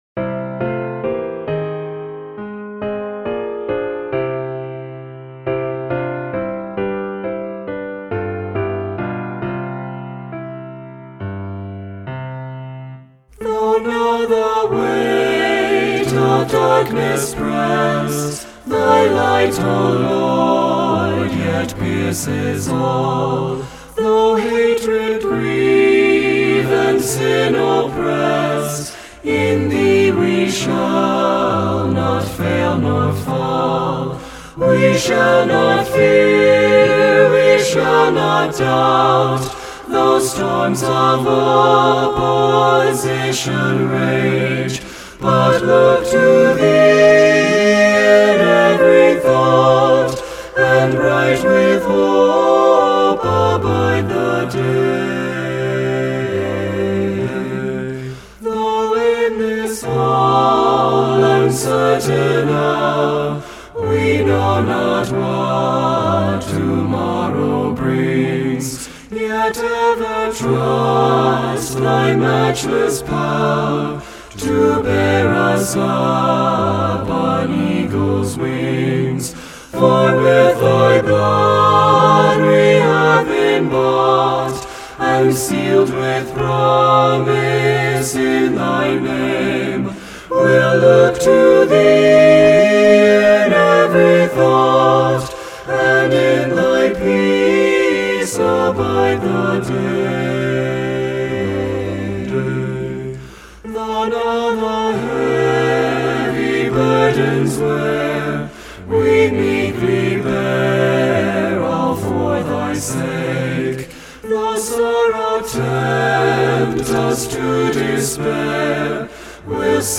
SATB Hymn
SATB Traditional Hymn